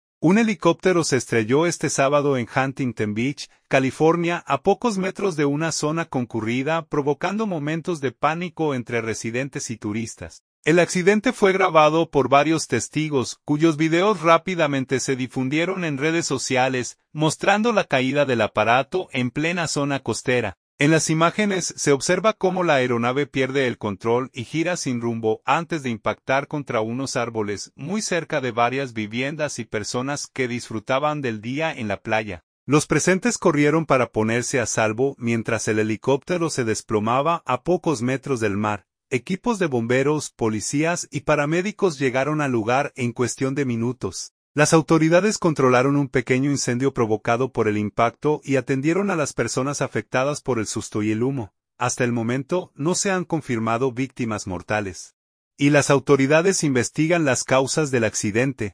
Un helicóptero se estrelló este sábado en Huntington Beach, California, a pocos metros de una zona concurrida, provocando momentos de pánico entre residentes y turistas. El accidente fue grabado por varios testigos, cuyos videos rápidamente se difundieron en redes sociales, mostrando la caída del aparato en plena zona costera.